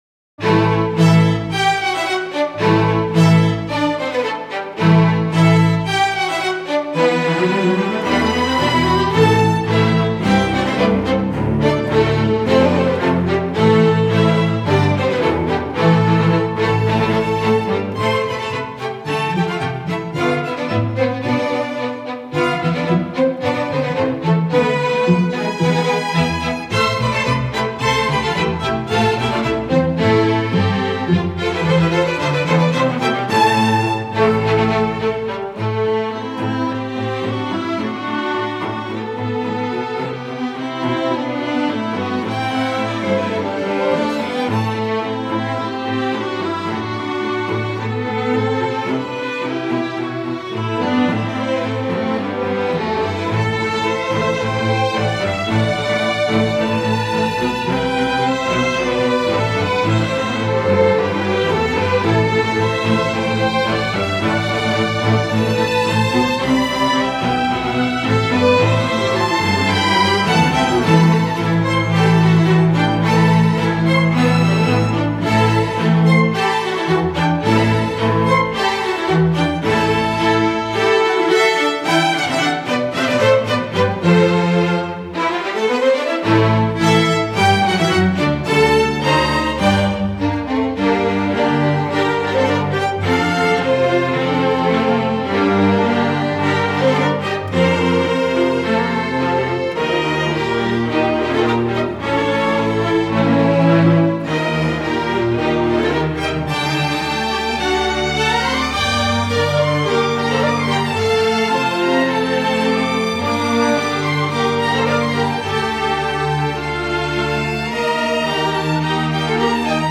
With compelling melodies and a heroic spirit
String Orchestra